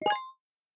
biometric_register_complete.ogg